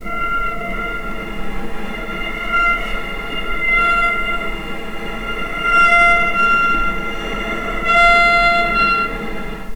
cello / sul-ponticello
vc_sp-F5-pp.AIF